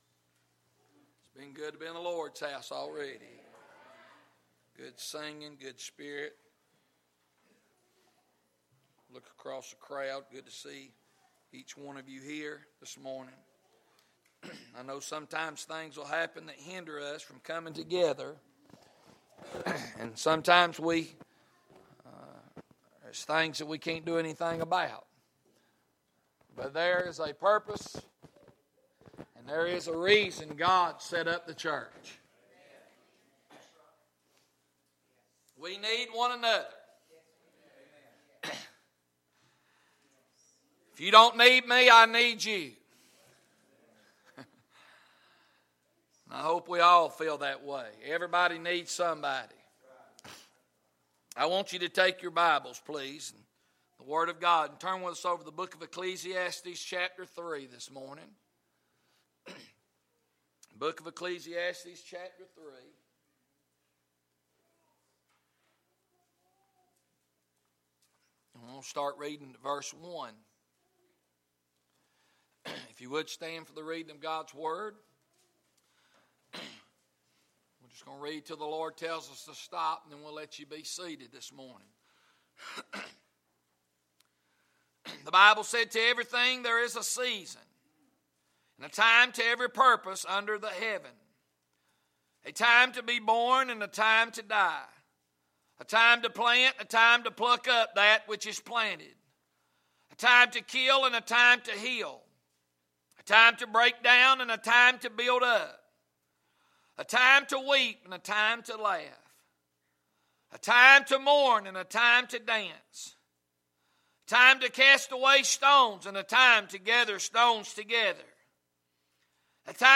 Message-Its-Time-To-Be-Saved.mp3